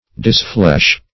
Disflesh \Dis*flesh"\